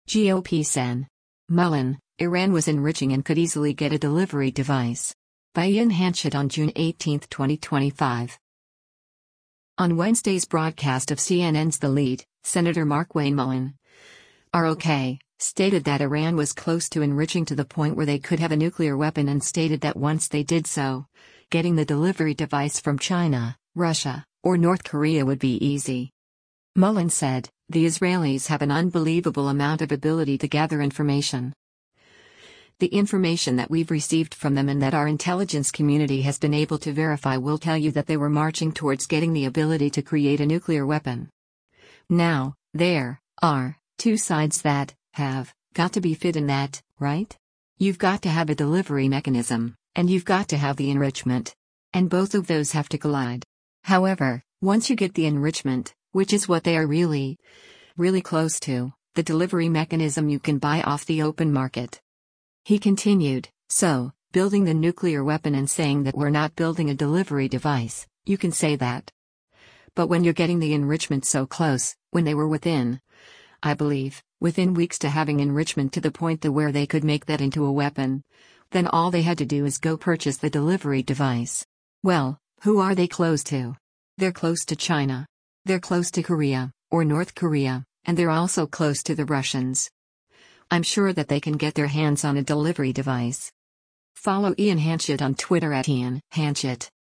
On Wednesday’s broadcast of CNN’s “The Lead,” Sen. Markwayne Mullin (R-OK) stated that Iran was close to enriching to the point where they could have a nuclear weapon and stated that once they did so, getting the delivery device from China, Russia, or North Korea would be easy.